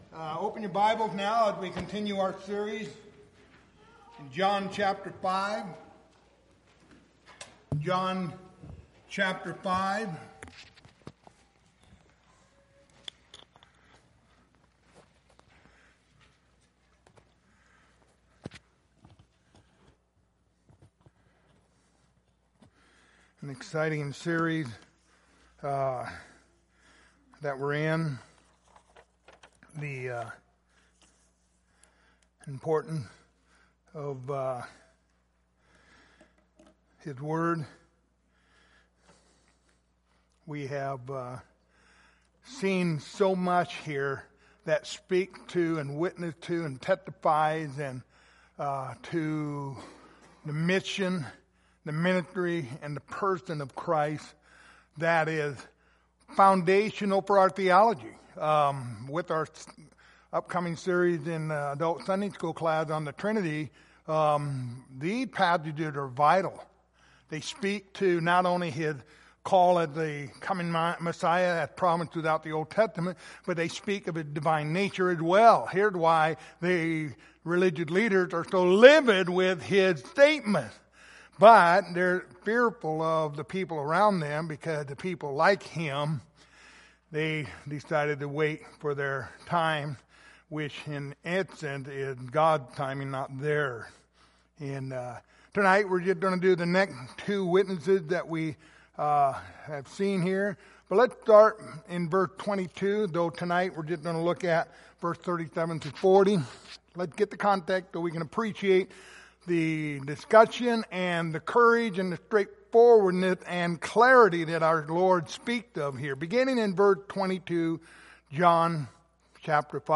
Passage: John 5:37-40 Service Type: Wednesday Evening